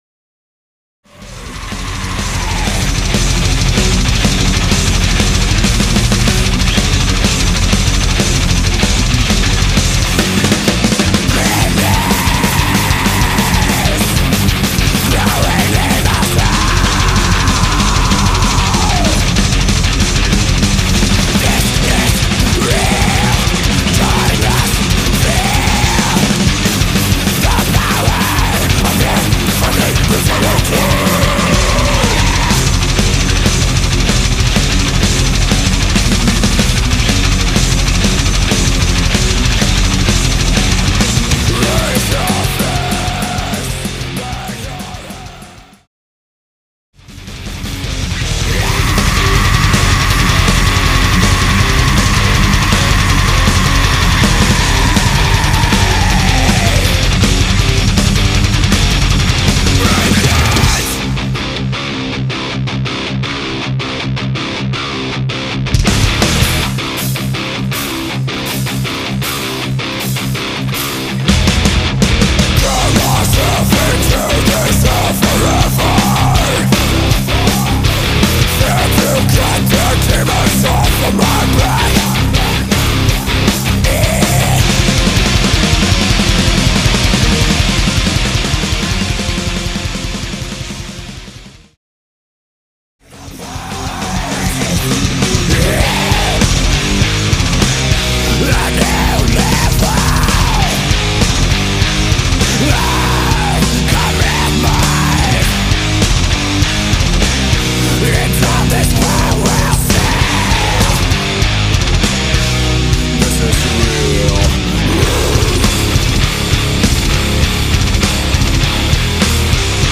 Death Metal
guitars and bass
vocals
drums
Recorded at SubSonic Society April 2005.